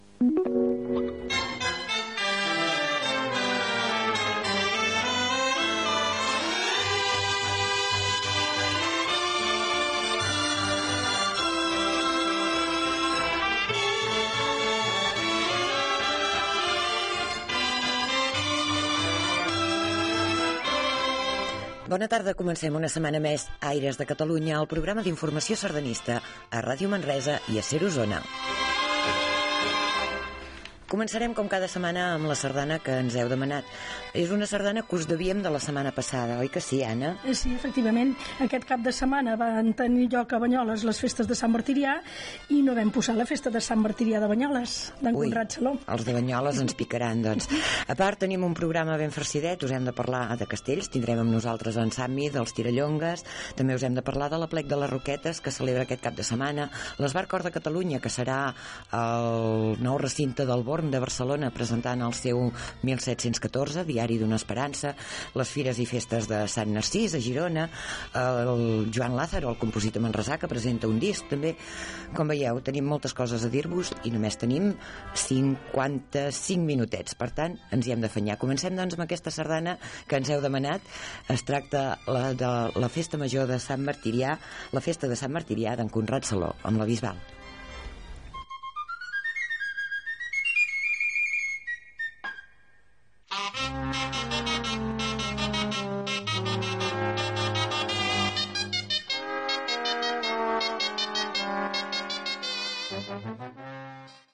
Sardana de sintonia, emissores que emeten el programa, presentació, la sardana demanada, sumari de continguts i sardana
Musical